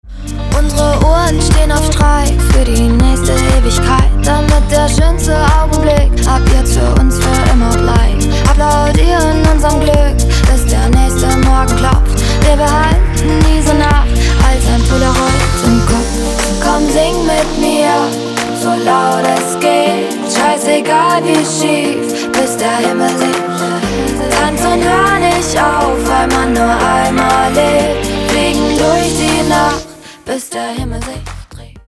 • Качество: 256, Stereo
поп
женский вокал
dance
красивый женский голос